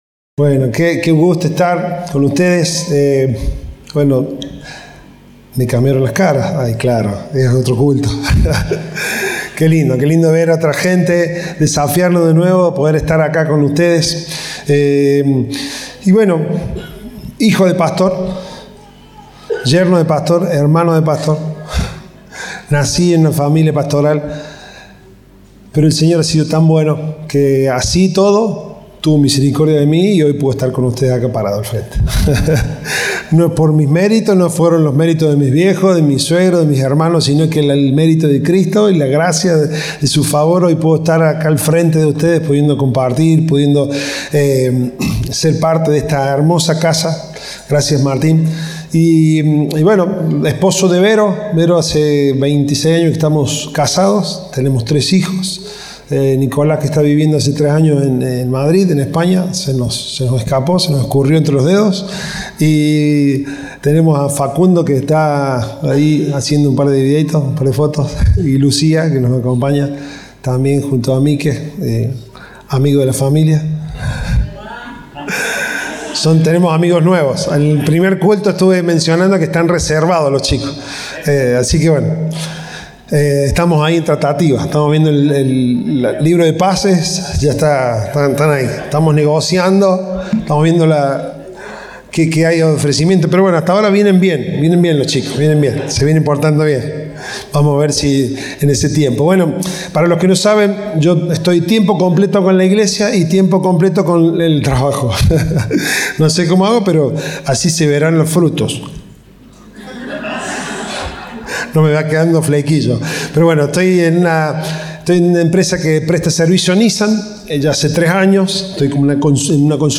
Compartimos el mensaje del Domingo de "Profeta en mi tierra".